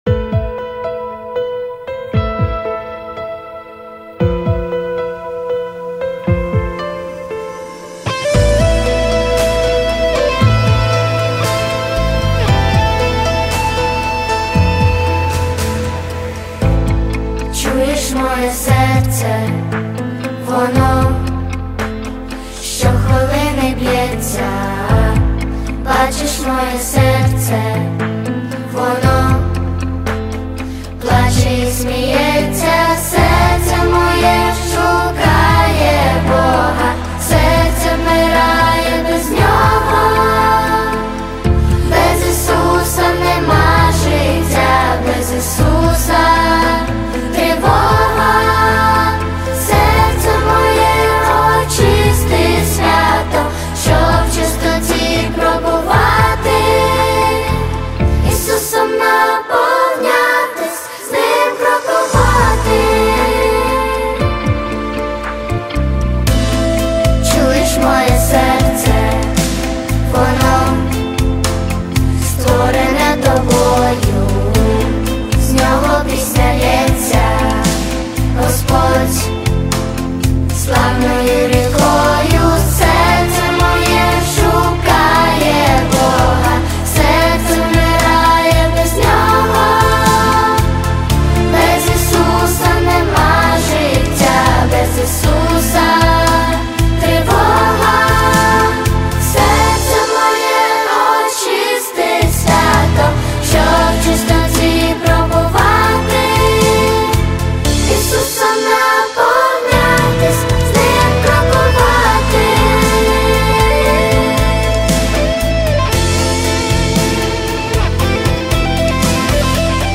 песня
102 просмотра 106 прослушиваний 16 скачиваний BPM: 115